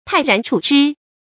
tài rán chǔ zhī
泰然处之发音
成语正音处，不能读作“chù”。